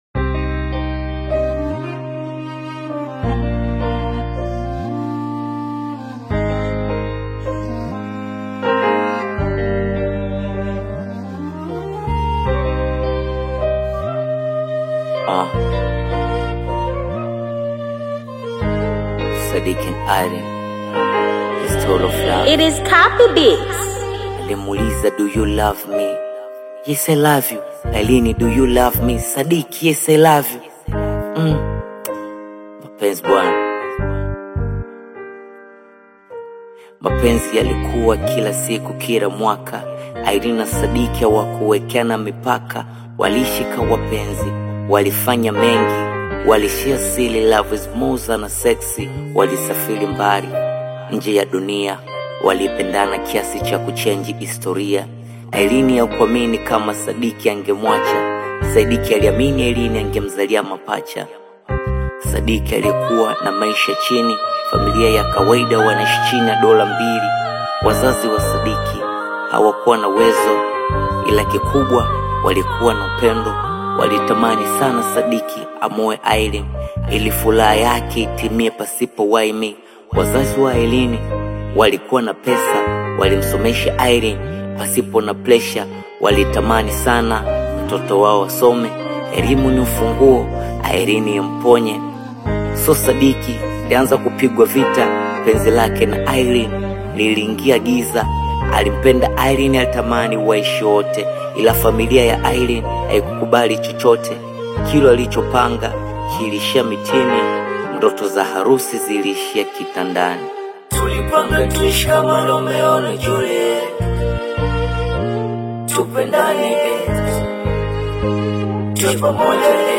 romantic Hip Hop track